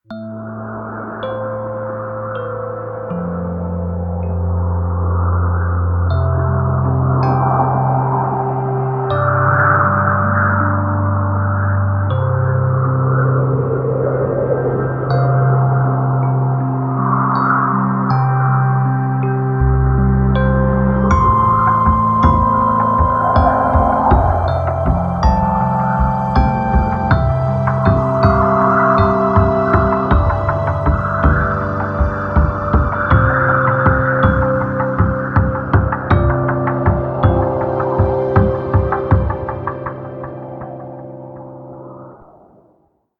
weather_alarm_snow2.ogg